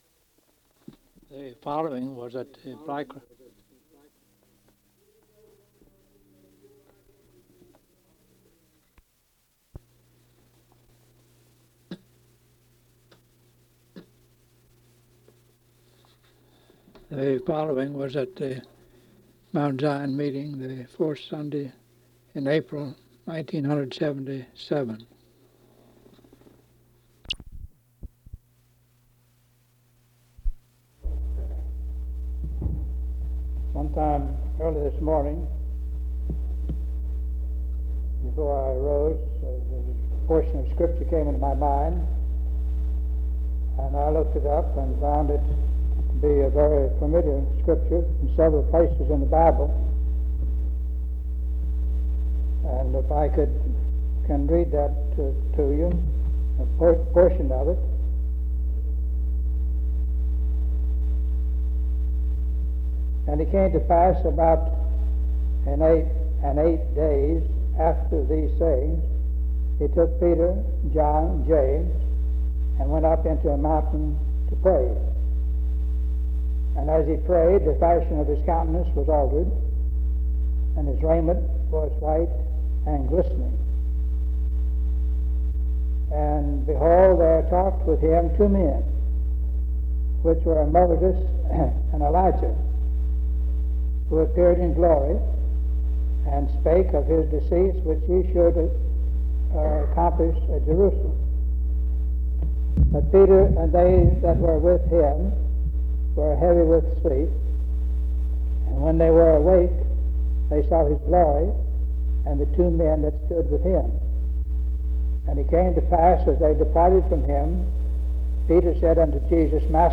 sermon collection